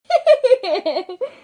咯咯笑
描述：真实的傻笑
标签： 声音 女孩 咯咯
声道立体声